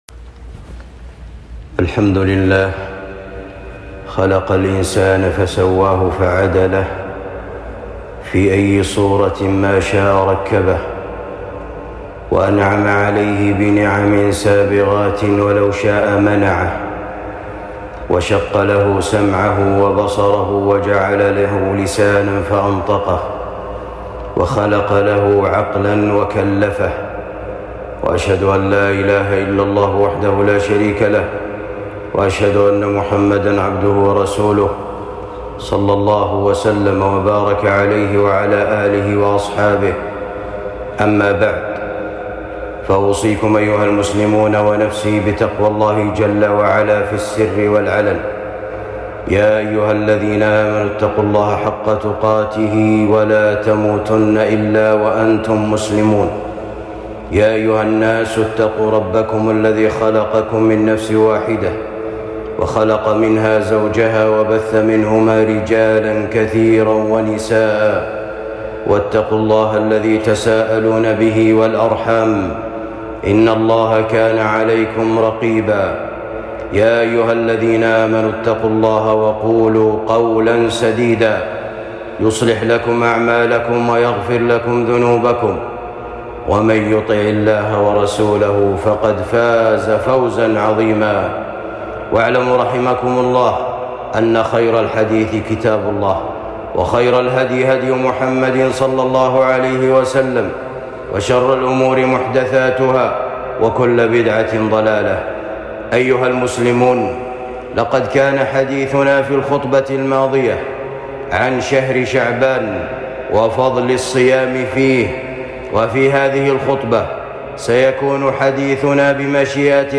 خطبة بعنوان ليلة النصف من شعبان